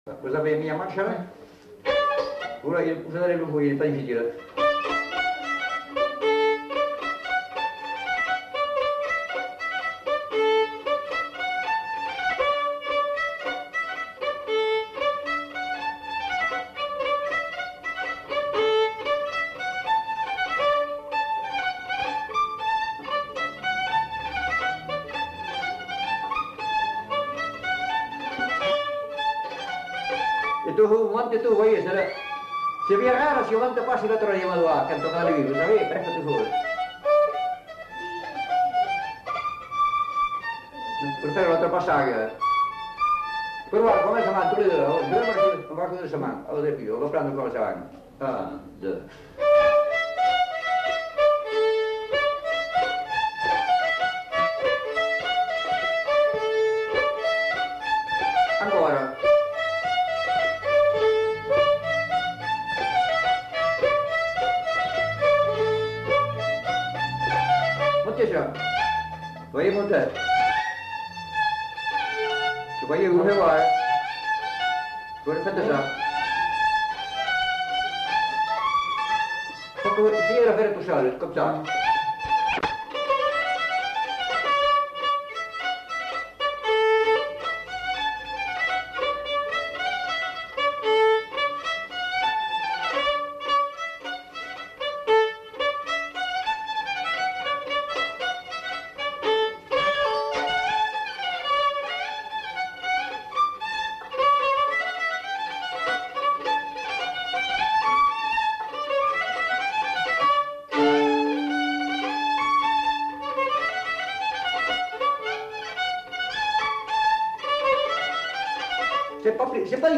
Lieu : Casteljaloux
Genre : morceau instrumental
Instrument de musique : violon
Danse : congo